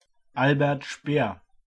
Berthold Konrad Hermann Albert Speer (/ʃpɛər/; German: [ˈʃpeːɐ̯]